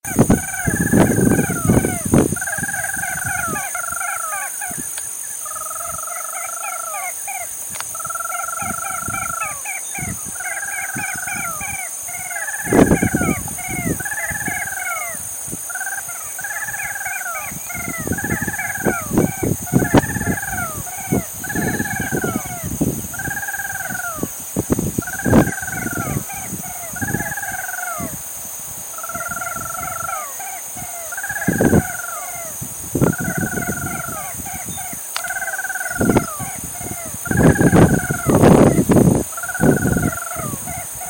Ash-throated Crake (Mustelirallus albicollis)
Sex: Both
Life Stage: Adult
Country: Argentina
Province / Department: Corrientes
Condition: Wild
Certainty: Photographed, Recorded vocal